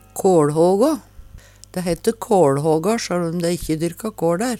kåLhågå - Numedalsmål (en-US)